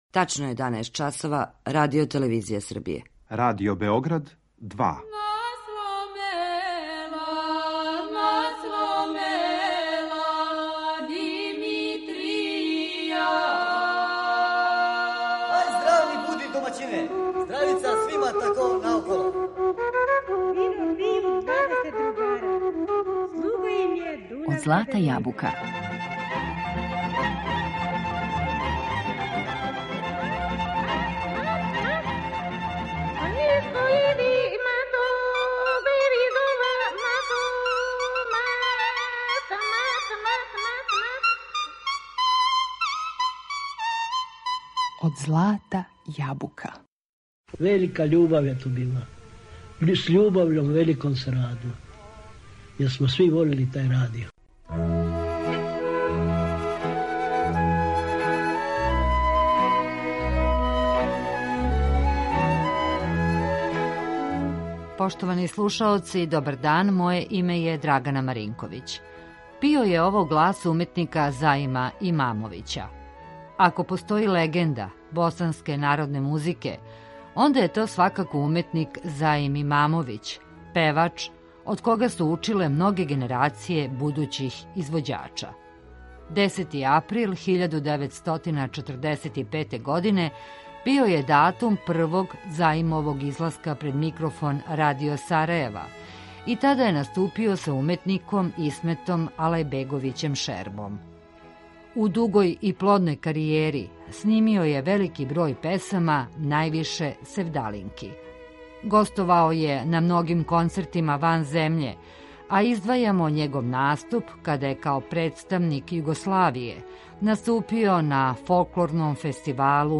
Имао је захвалну боју гласа, препознатљиву и сетну, а сваку песму је украшавао на свој начин, посебним украсима.